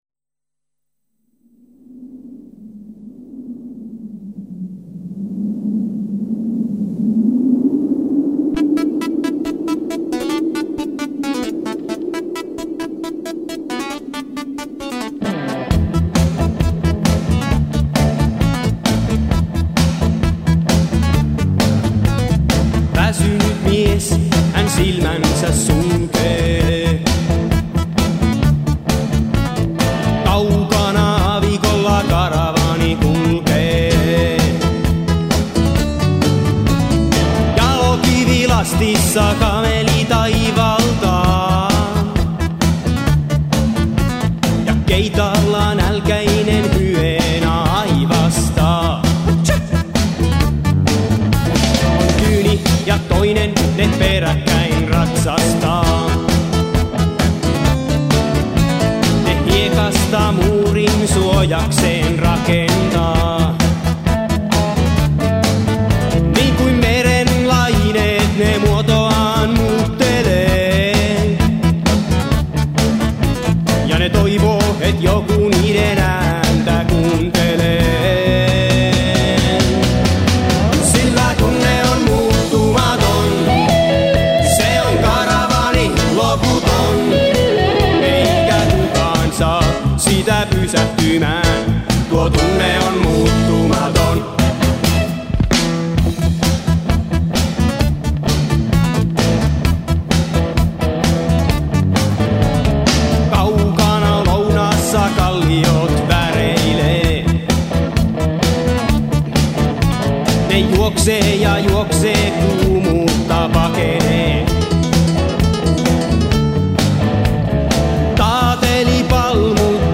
laulu, taustalaulu, basso
koskettimet, taustalaulu
rummut, taustalaulu
kitarat